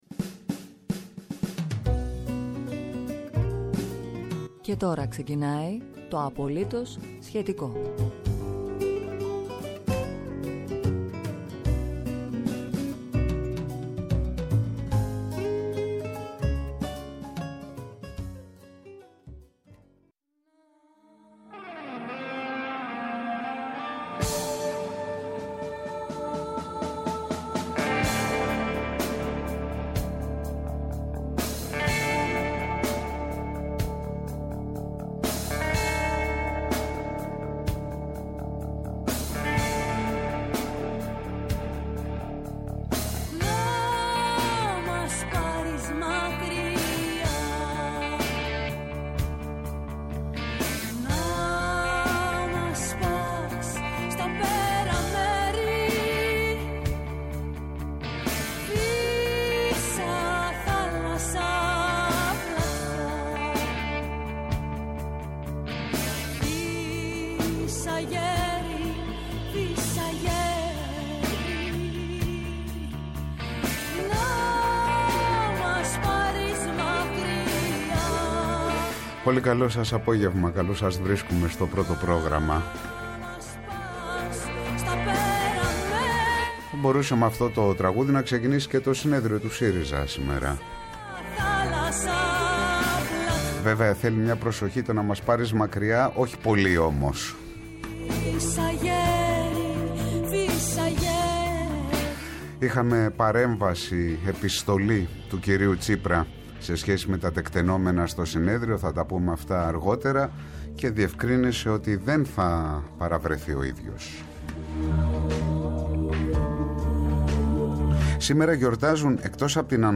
ραδιοφωνικές διαδρομές σε Ελλάδα και Κόσμο, σε Πρόσωπα και Κοινωνία.